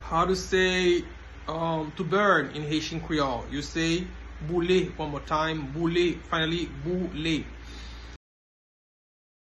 Listen to and watch “Boule” pronunciation in Haitian Creole by a native Haitian  in the video below:
Burn-in-Haitian-Creole-Boule-pronunciation-by-a-Haitian-teacher.mp3